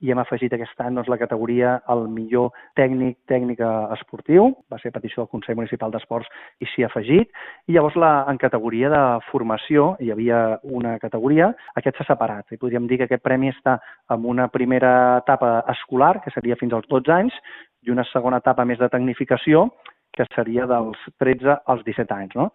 La recuperada Festa de l’Esport presenta dues novetats importants quant als premis que s’entregaran a la gala del pròxim 31 de gener a la polivalent de La Fàbrica. Ho avança el regidor d’Esports de l’Ajuntament de Calella, Manel Vicente, en declaracions a Ràdio Calella TV: